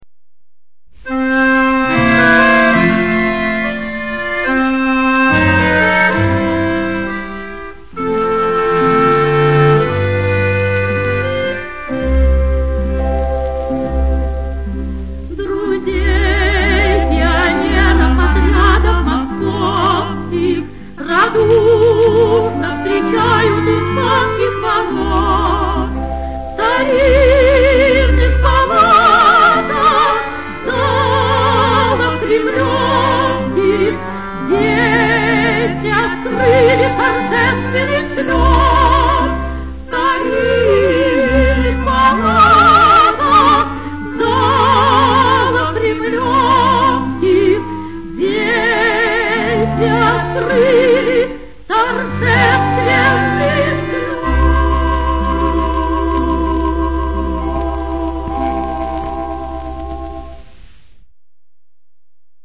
russian music